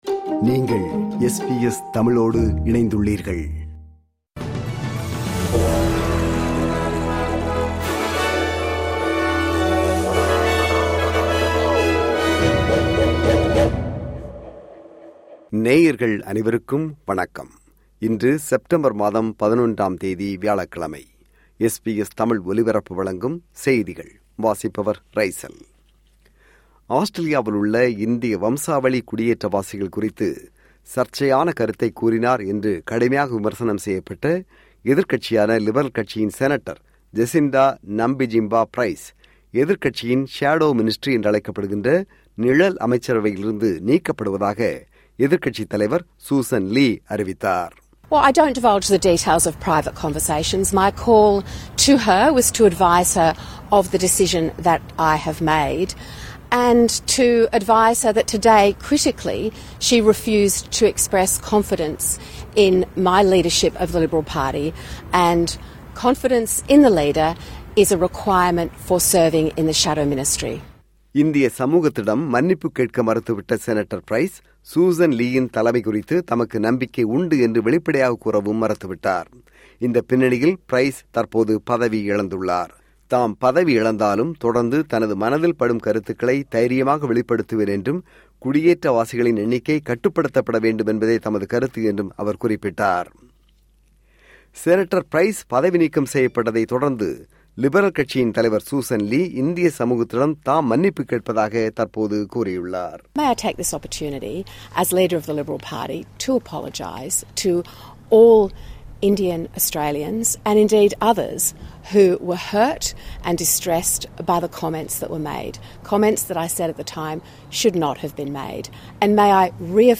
இன்றைய செய்திகள்: 11 செப்டம்பர் 2025 வியாழக்கிழமை
SBS தமிழ் ஒலிபரப்பின் இன்றைய (வியாழக்கிழமை 11/09/2025) செய்திகள்.